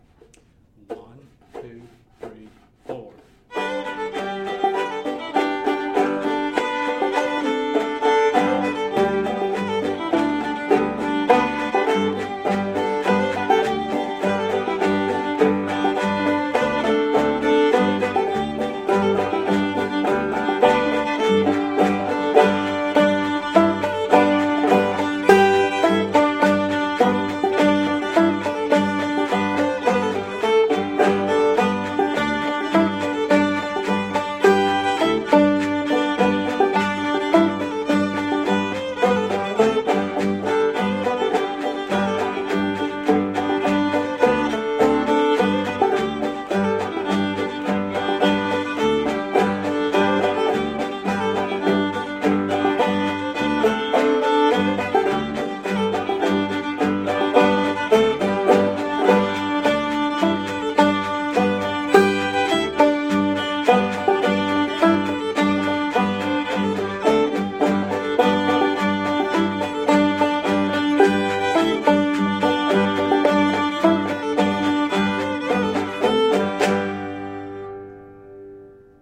We can play Old-Time music